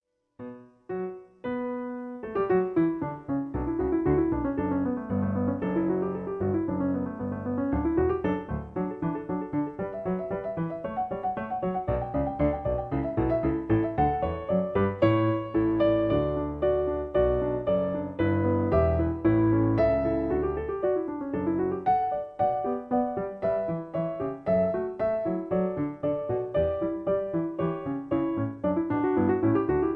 In B. Piano Accompaniment